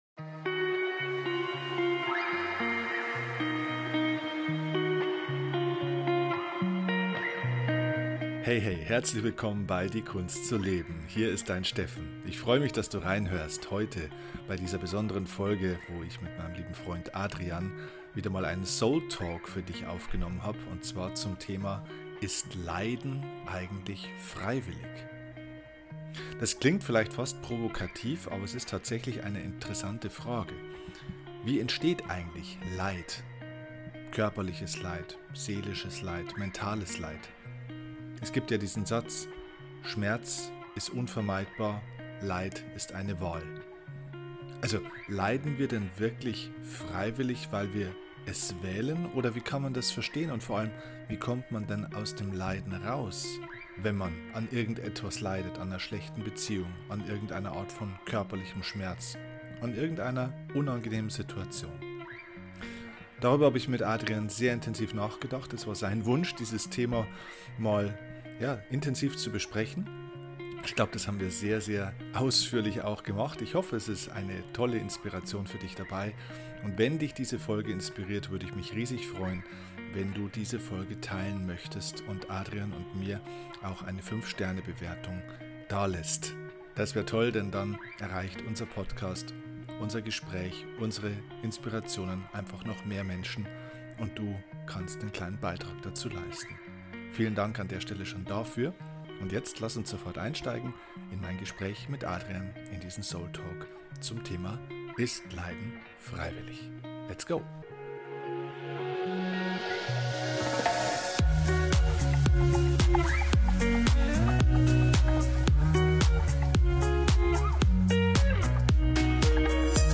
Wie immer bei SOUL TALK: Kein Skript.